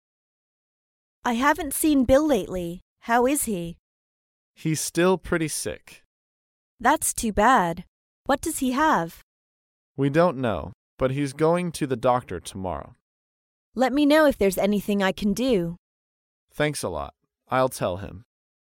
在线英语听力室高频英语口语对话 第97期:询问朋友病况的听力文件下载,《高频英语口语对话》栏目包含了日常生活中经常使用的英语情景对话，是学习英语口语，能够帮助英语爱好者在听英语对话的过程中，积累英语口语习语知识，提高英语听说水平，并通过栏目中的中英文字幕和音频MP3文件，提高英语语感。